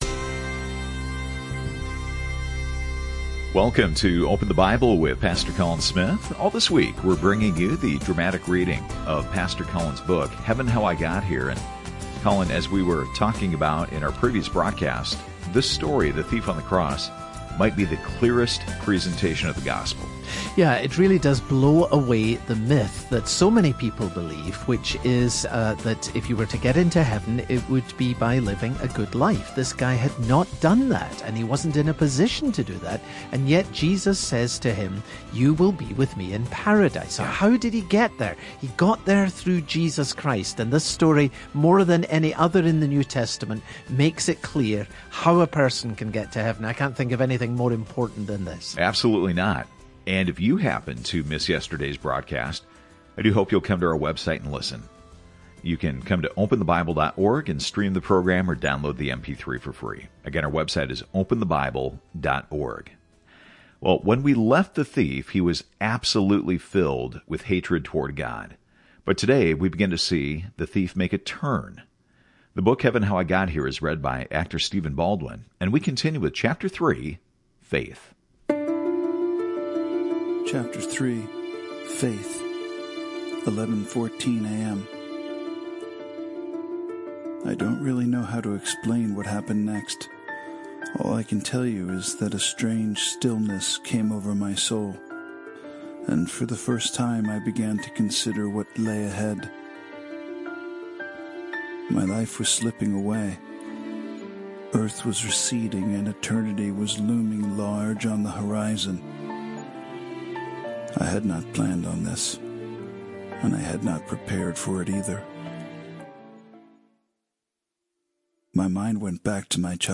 This radio broadcast features narration by actor Stephen Baldwin.